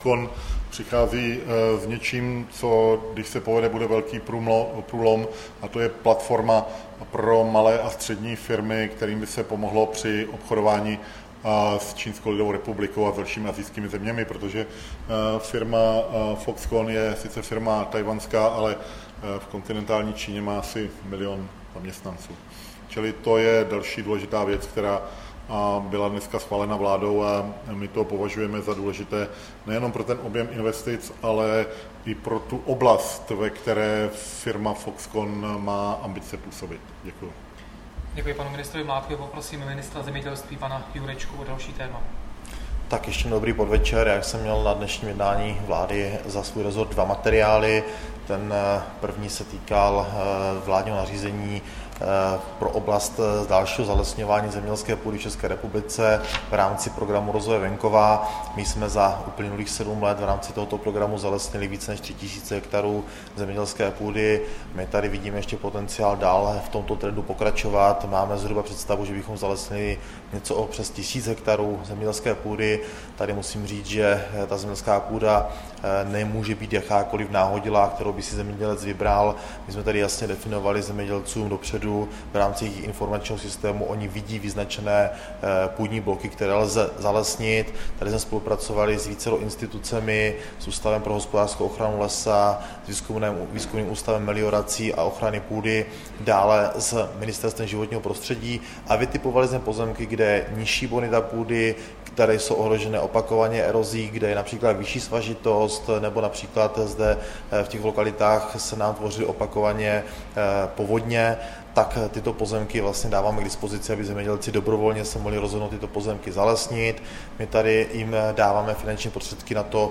Tisková konference po jednání vlády v Moravskoslezském kraji, 22. července 2015